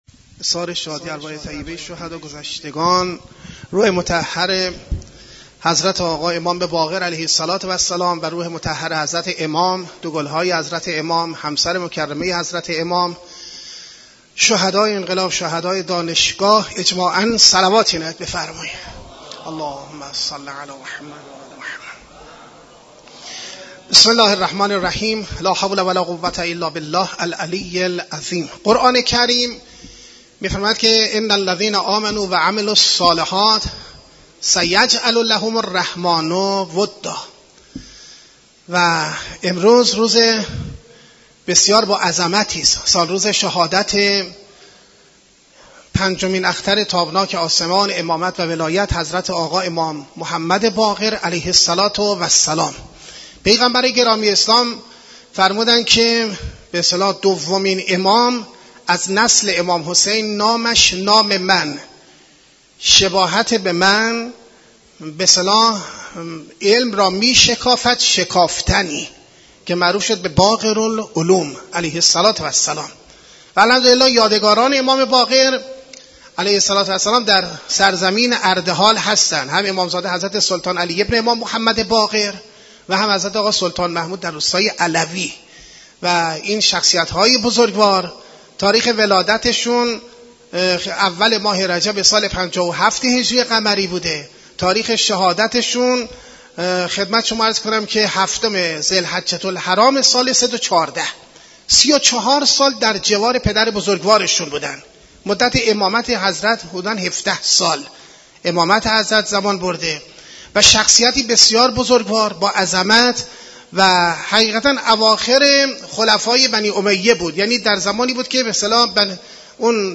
سخنرانی
در مسجد دانشگاه کاشان